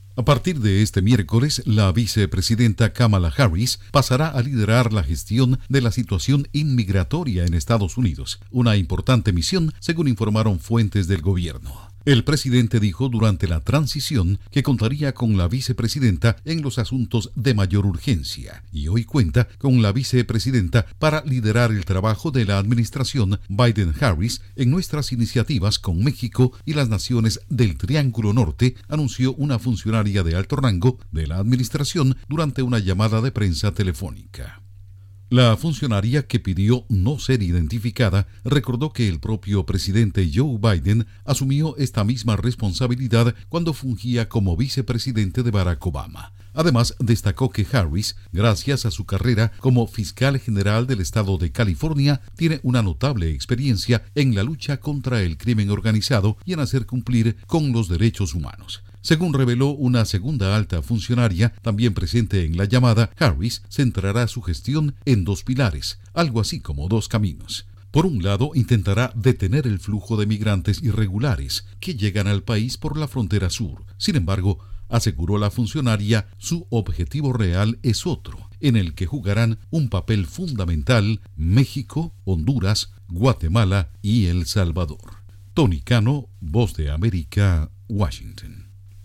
La vicepresidenta Kamala Harris toma las riendas de la gestión migratoria. Informa desde la Voz de América en Washington